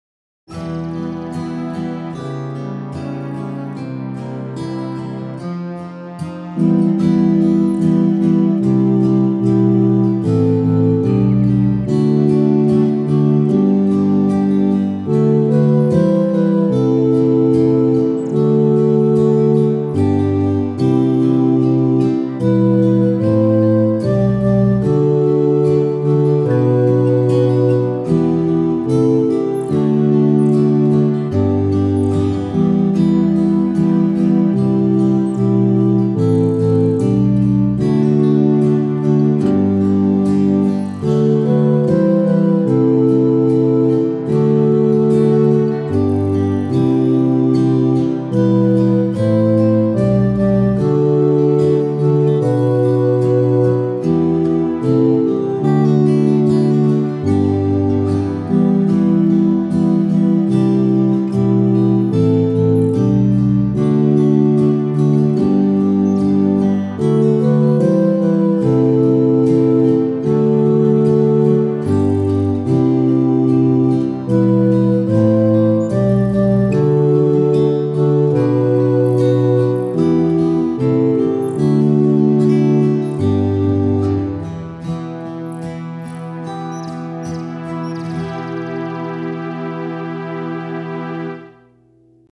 A beautiful chant